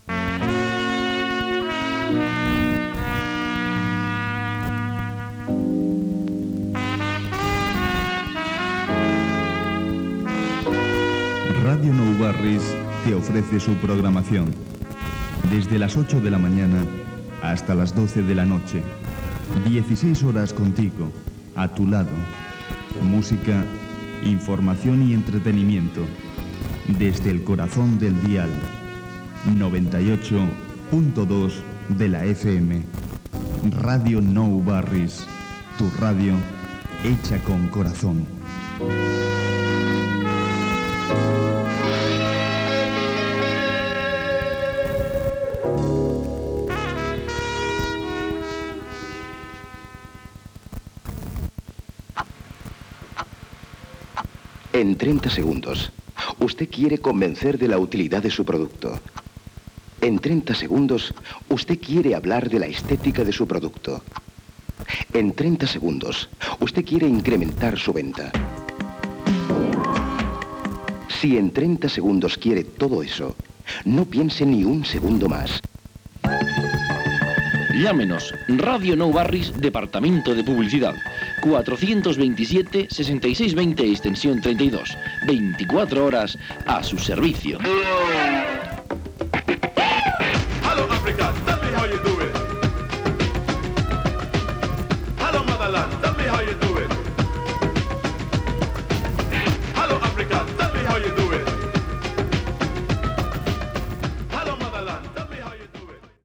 Indicatiu, anunci del departament de publicitat de l'emissora, tema musical.
FM